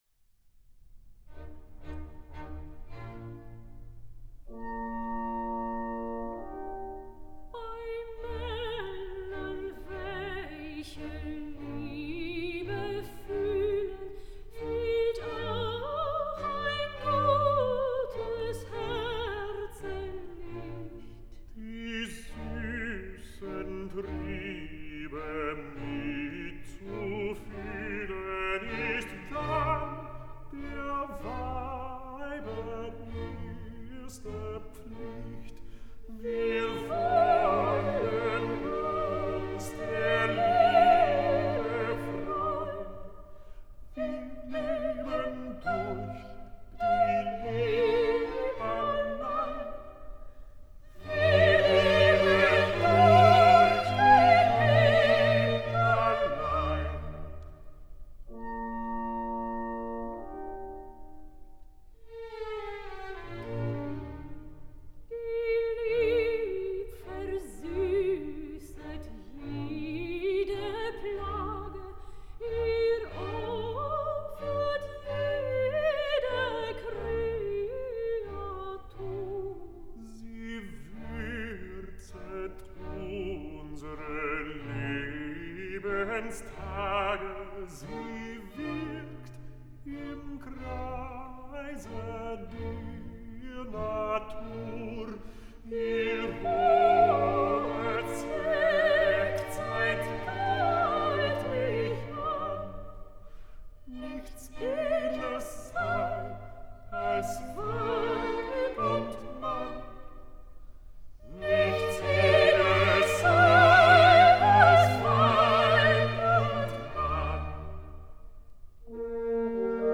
Música clásica
Canto